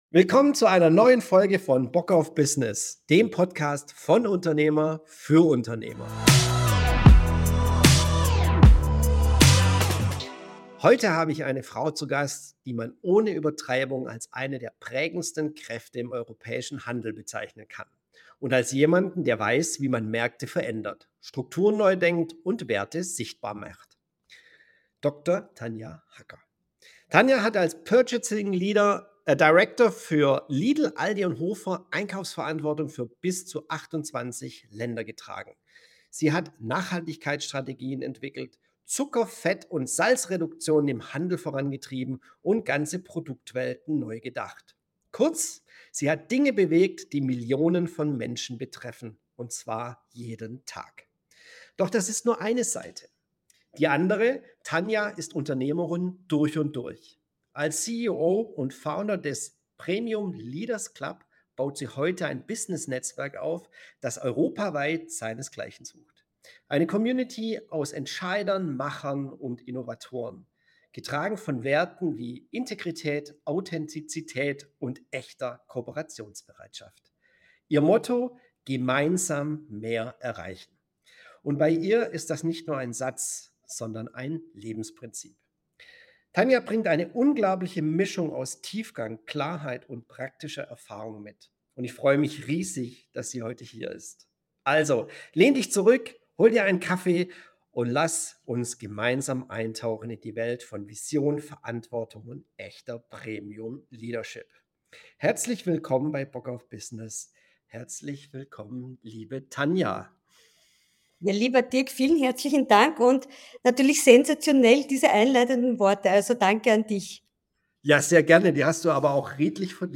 Ein Gespräch über Leadership mit Substanz – für Unternehmer, die langfristig gestalten wollen.